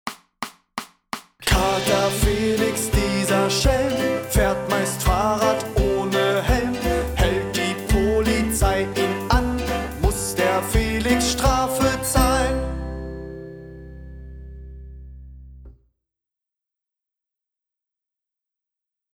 schnell + Minis